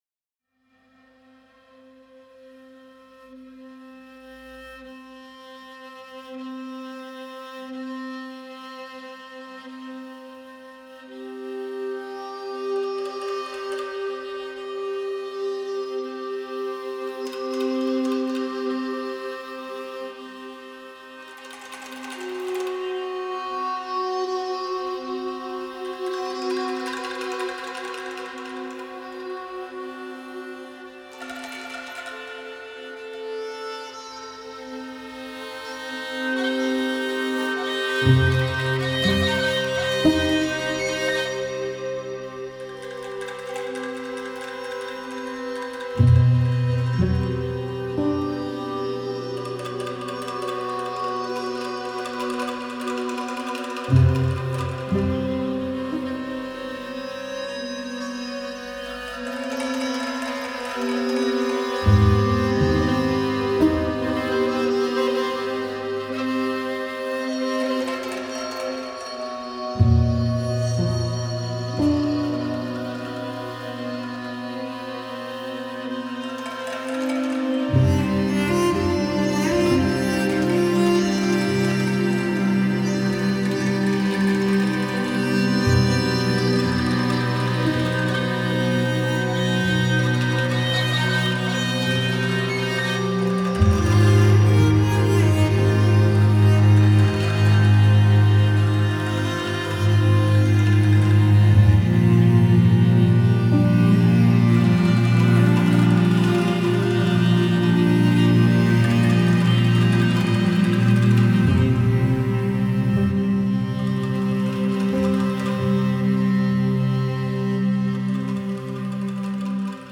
Iconic Indonesian percussion
• Explore the resonant beauty of the Indonesian angklung
Bamboo music